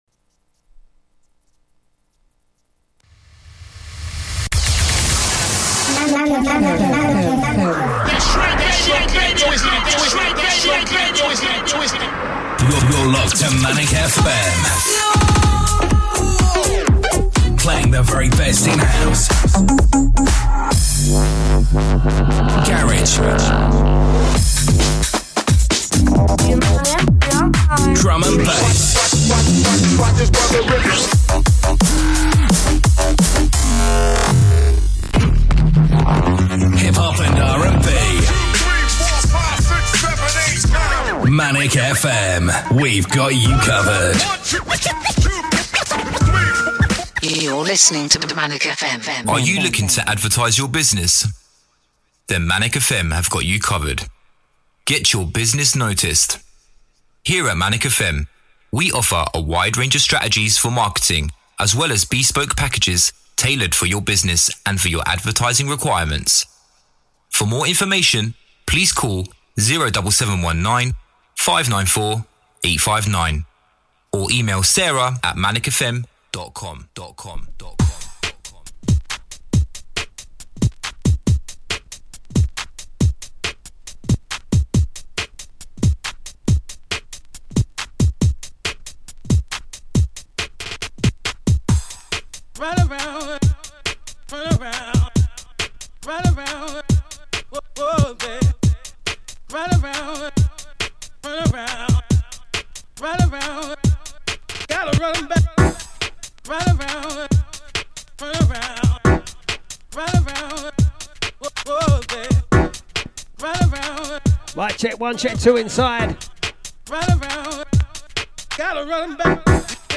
UKG VINYL